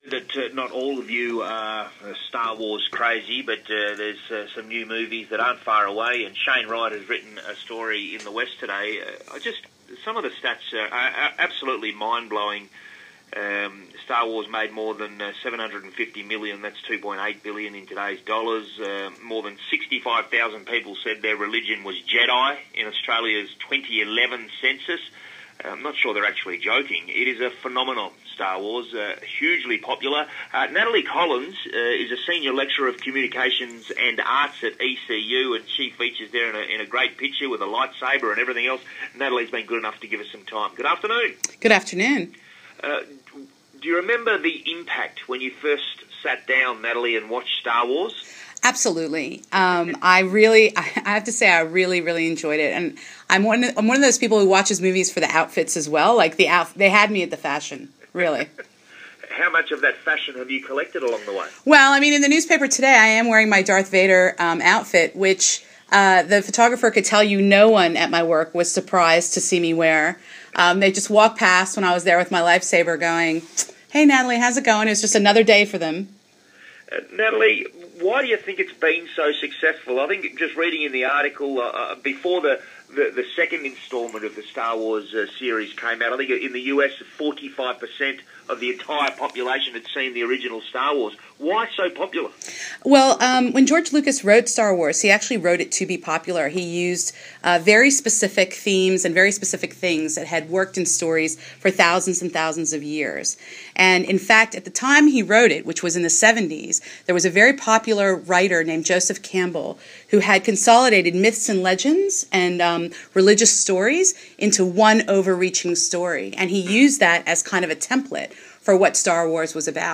star-wars-interview.m4a